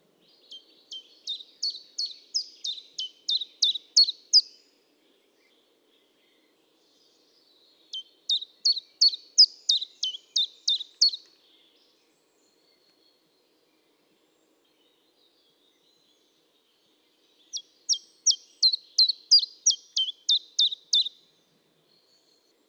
Zilpzalp Gesang
• Der Name „Zilpzalp“ ist eine Lautnachahmung seines Gesangs.
Zilpzalp-Gesang-Voegel-in-Europa.wav